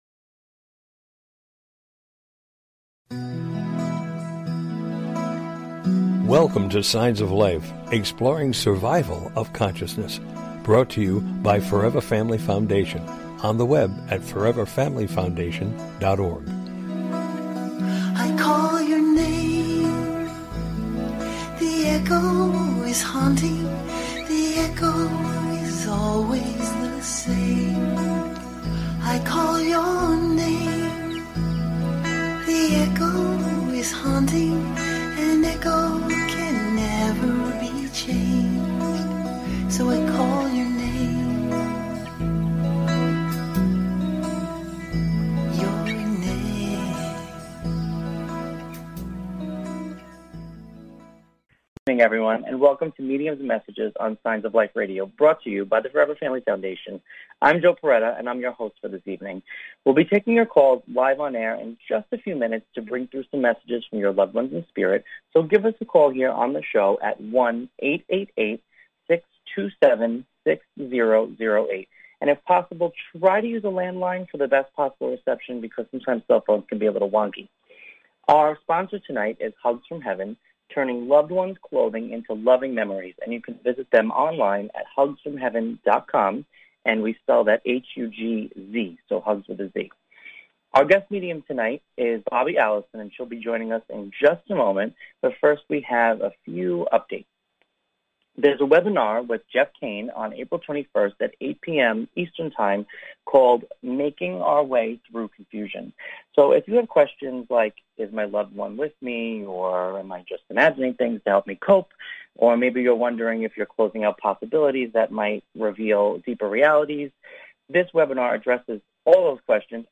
Talk Show Episode
Interviewing guest medium